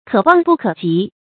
可望不可即 kě wàng bù kě jí
可望不可即发音
成语正音 即，不能读作“jì”。